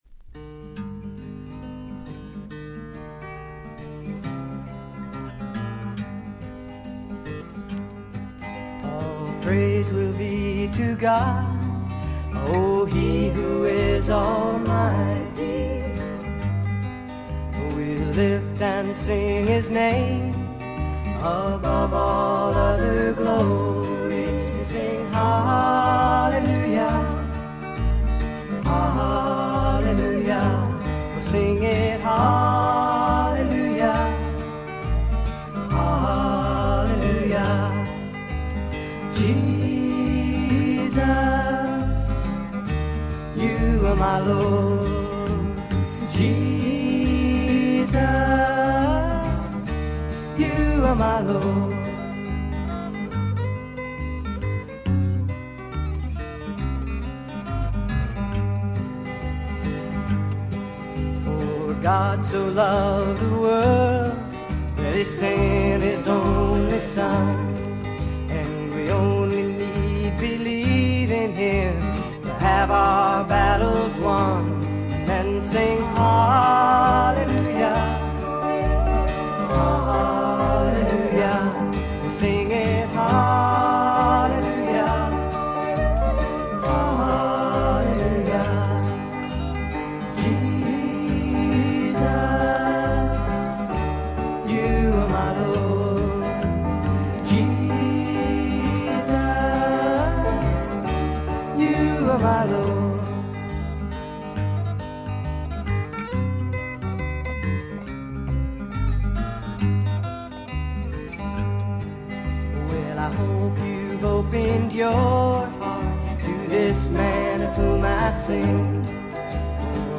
フィメールによるバックヴォーカルや寄り添うようなヴァイオリンの調べにうっとり。